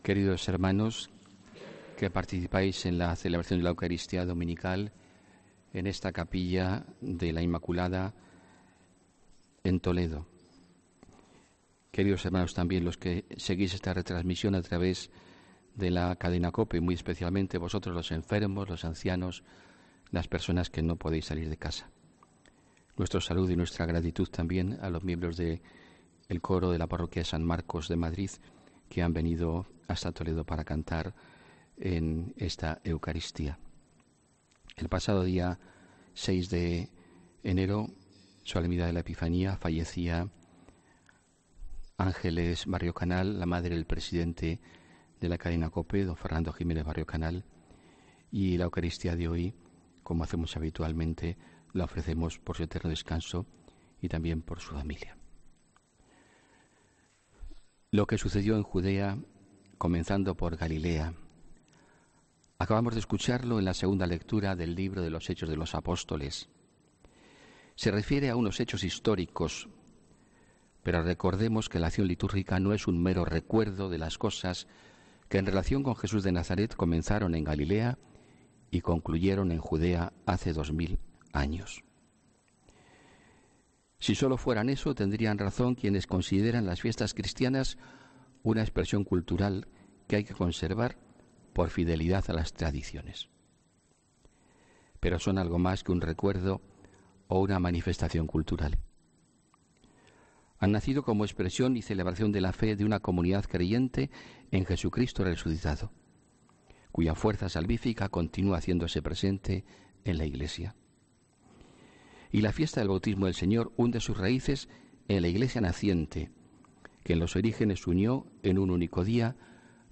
HOMILIA 12 ENERO 2019